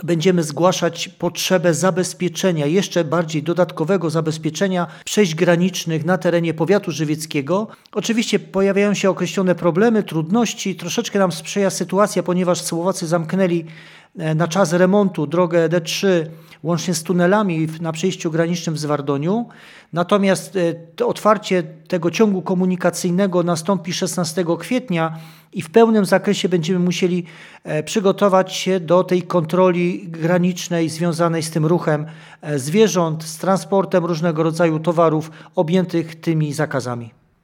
Postępujące zagrożenie pryszczycą jest ogromnym wyzwaniem dla służb, mówi wicestarosta żywiecki Stanisław Kucharczyk.